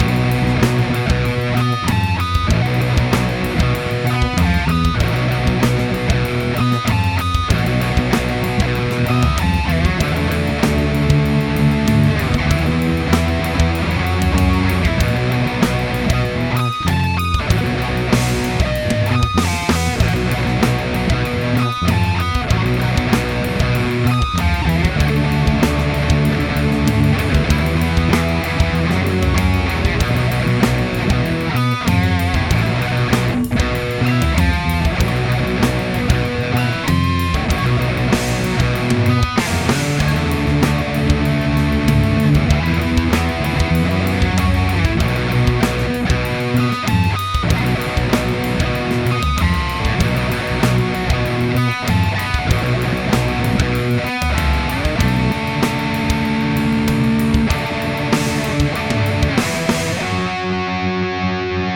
The May sessions were pretty much just one continuous improv with no planning or coherence.